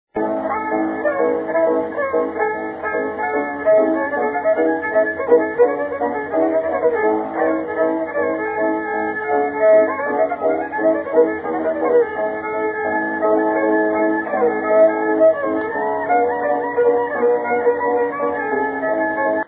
Subject Music, Scottish Fiddle Music, Strathspey, Reel, Drumblair
This excellent strathspey, with its 'spiccato' (off-the-string) bowing is still popular as it was when Skinner wrote: 'Several players have won medals with this tune'.
Tempo - strathspey - 20 seconds.